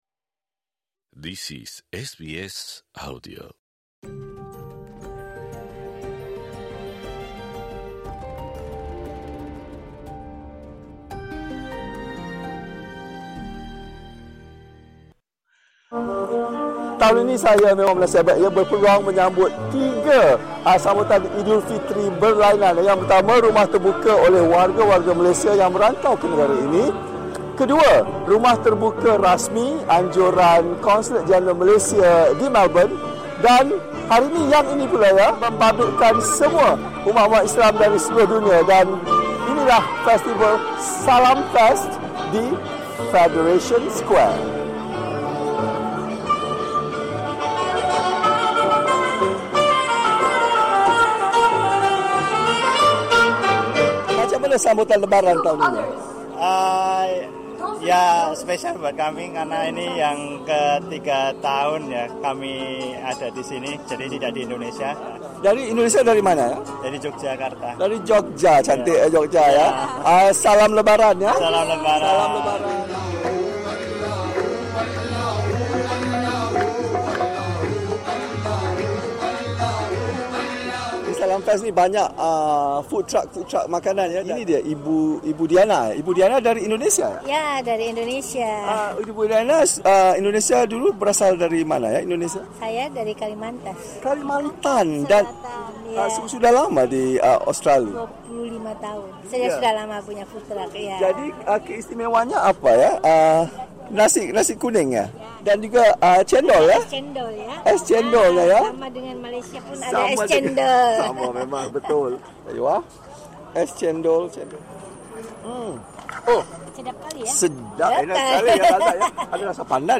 Pesta tahunan SalamFest 2025 yang berlansung di Federation Square, Melbourne, kali ini berjaya menarik beribu pelawat yang datang untuk menikmati suasana Hari Raya tahun ini. SBS Bahasa Melayu berpeluang menyaksikan pelbagai persembahan budaya serta menemubual umat Islam berbilang kaum yang menyambut kemeriahan Eidul Fitri.